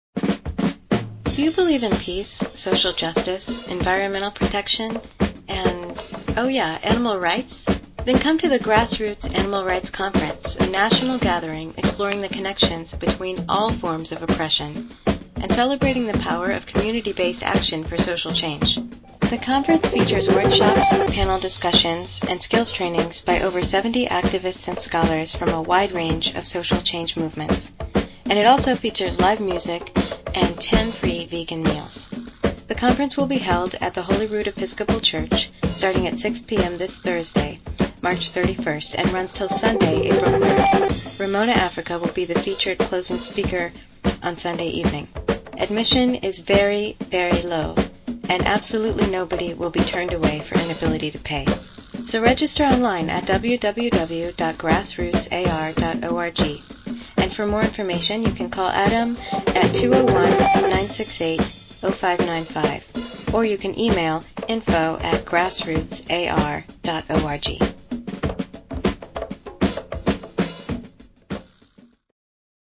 GARC_-_PSA_(low_bandwidth).mp3